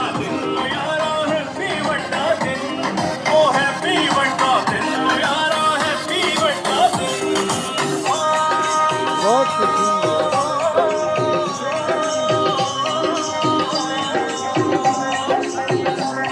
Happy wadda din ("Happy big day!") playing beside the nativity scene in the "French" (Christian) colony in Islamabad, Christmas Day